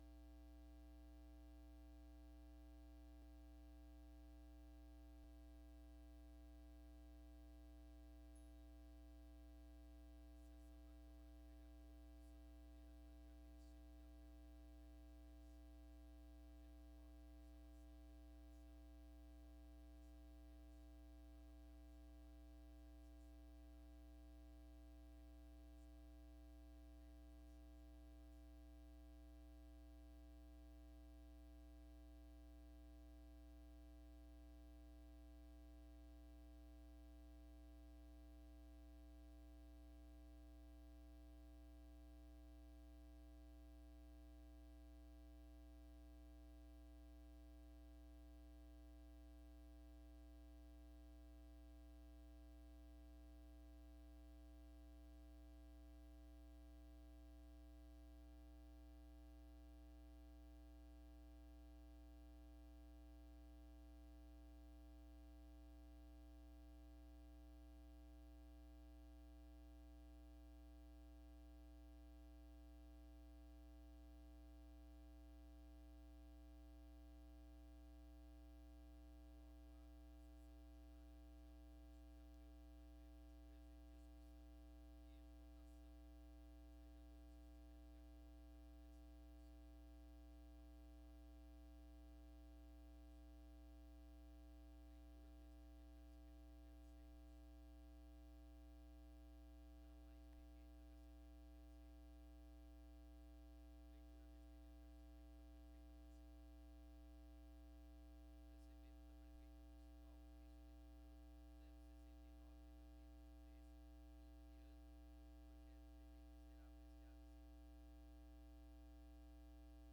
Local: Plenário Pedro Parenti
Áudio da Sessão